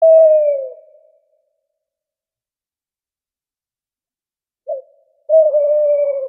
Kategorien Tierstimmen